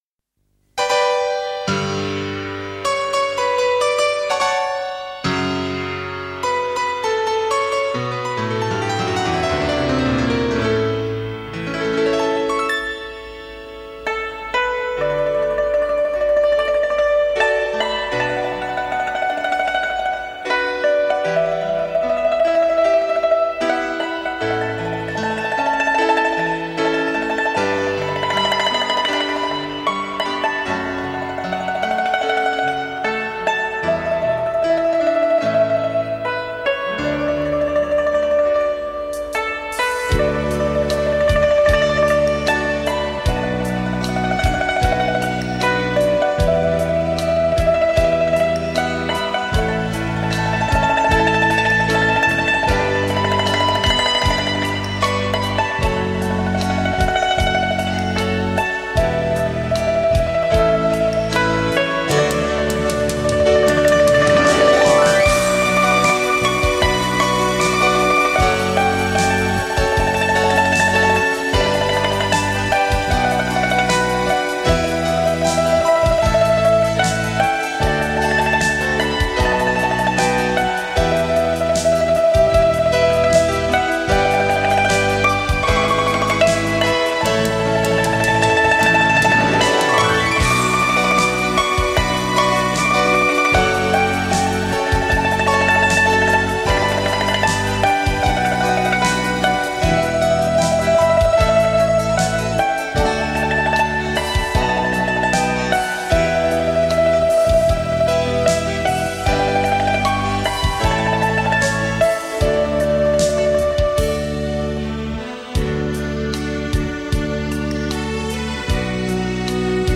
随轻松的小调民乐一起 进入充满诗情画意的田园
放慢如花的乐曲 犹如放慢焦急而匆忙的脚步
闲暇优雅的民乐尤如天籁，能散发出奇特的芬香。